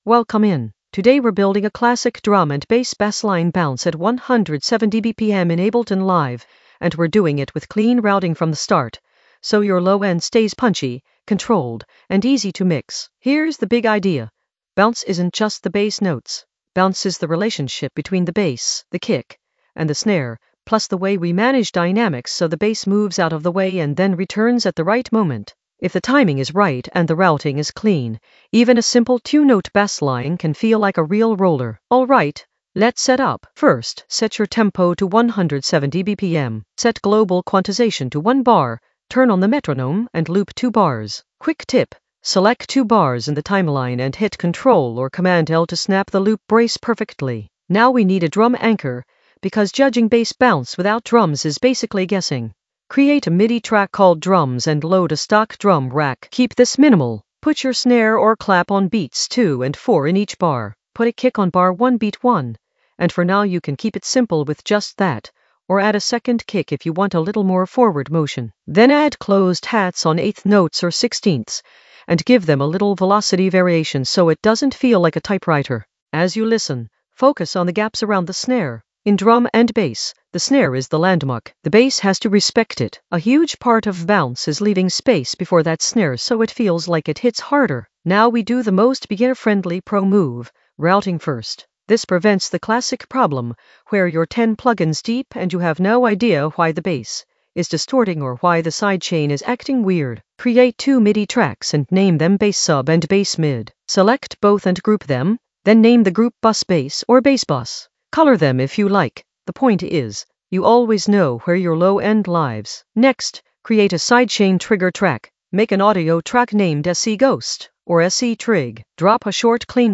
Narrated lesson audio
The voice track includes the tutorial plus extra teacher commentary.
An AI-generated beginner Ableton lesson focused on Bassline bounce at 170 BPM with clean routing in the Basslines area of drum and bass production.